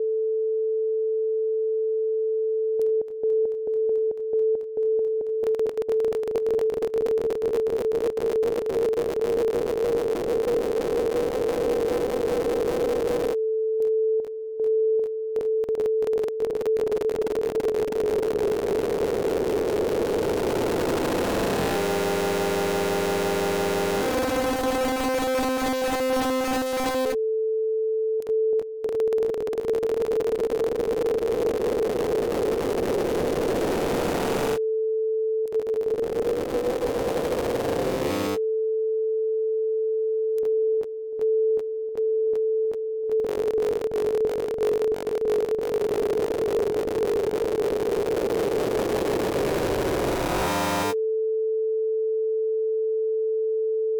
However, as I continue to test the synth, it is evident how essentially different the sound quality of the glitch it produces is compared to the sound that can be obtained from the PureData patch.